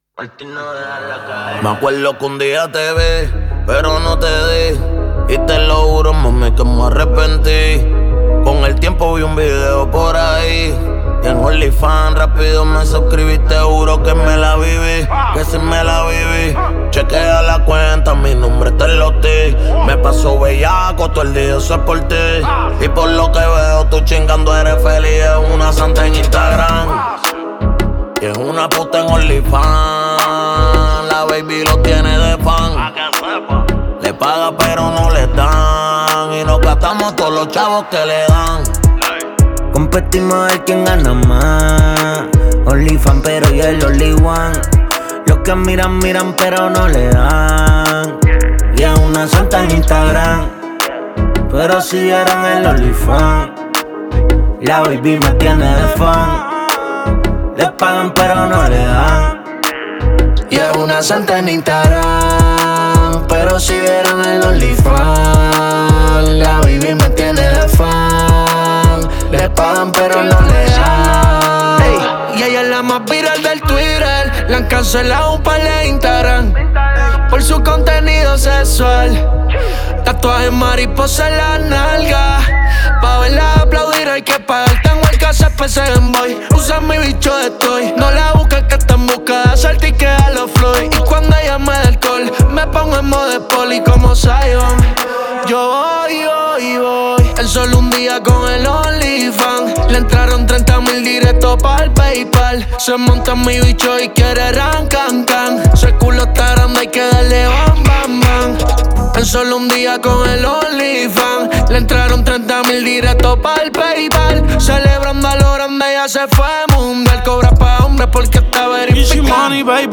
Músicas | Reggaeton